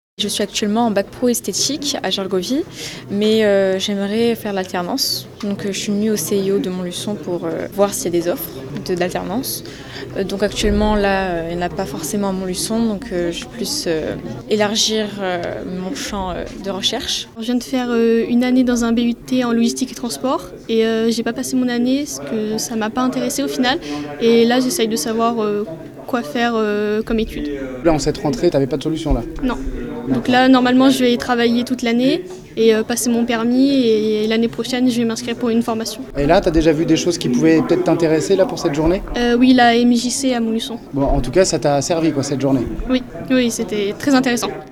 Plusieurs dizaines de jeunes ont été au rendez-vous de la journée de mobilisation du CIO de Montluçon hier.